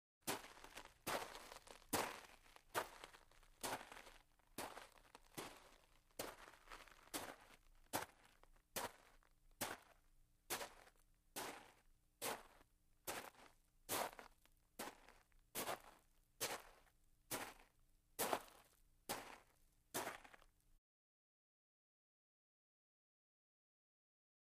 Footsteps On Gravel, Single